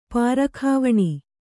♪ pārakhāvaṇi